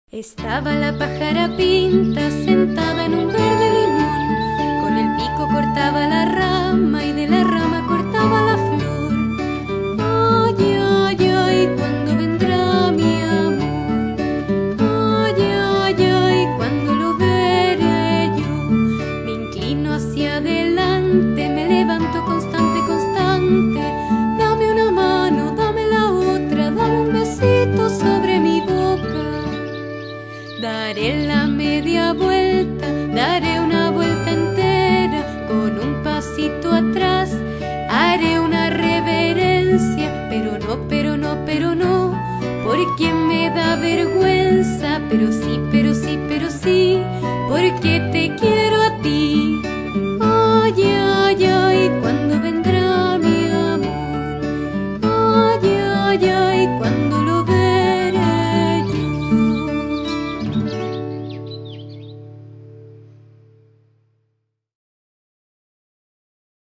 Música infantil